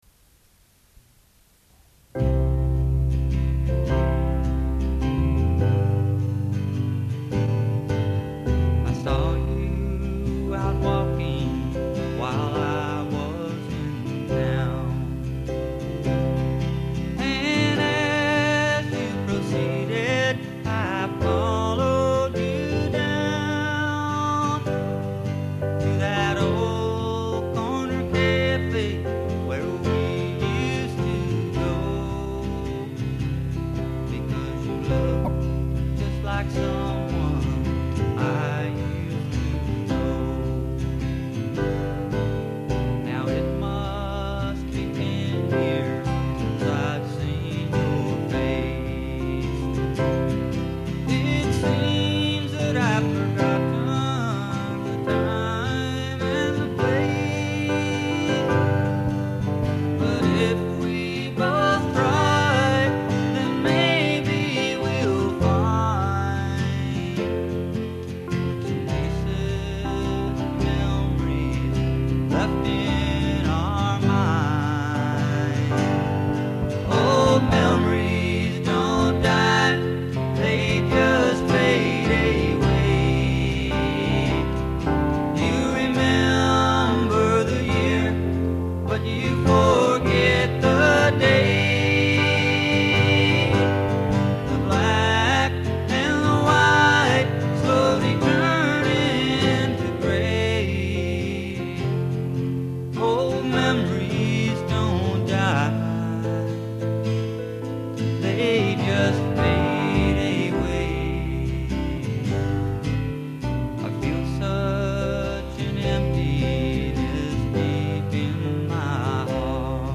4-TRACK DEMO